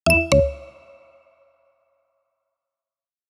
notification_sound.wav